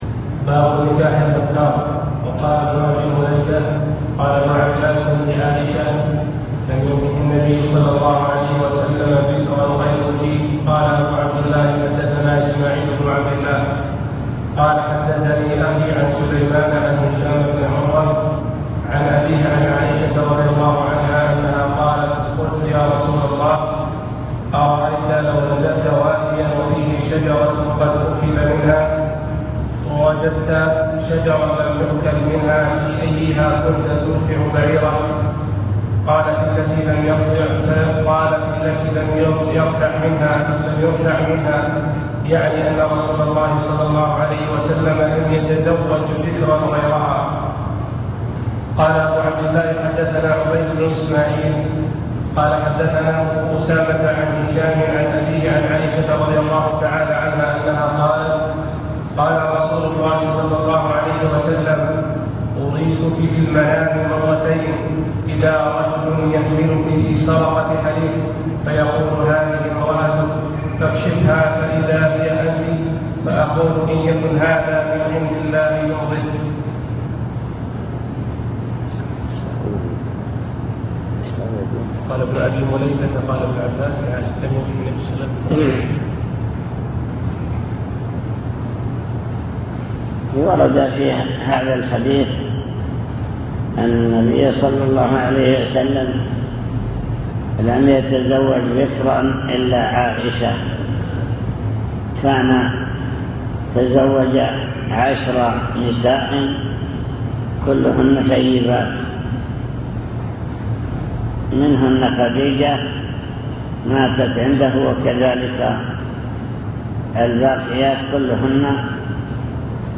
المكتبة الصوتية  تسجيلات - كتب  شرح كتاب النكاح من صحيح البخاري